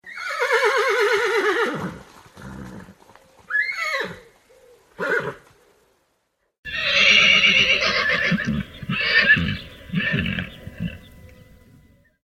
horses.mp3